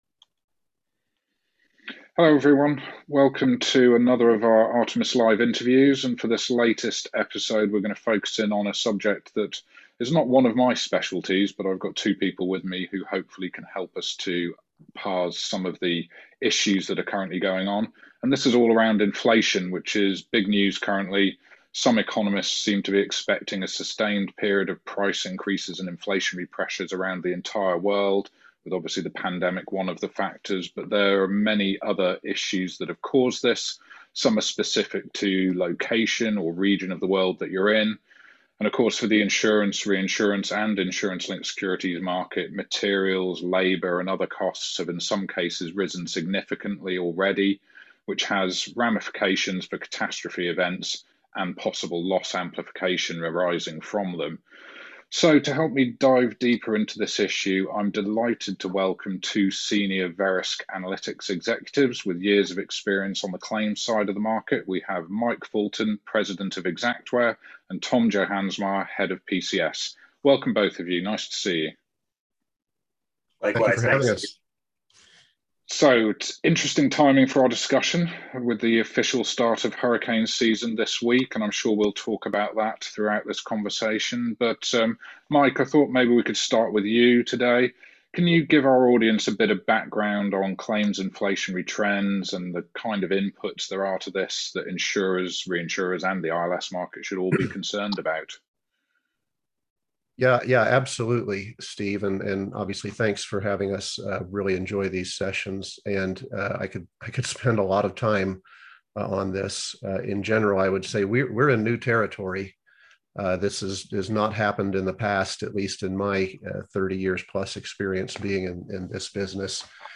This interview features a discussion of inflationary pressures affecting the global insurance industry, what these mean and how reinsurance and ILS market participants may deal with the effects of it, including hedging, retrocession and other portfolio management opportunities.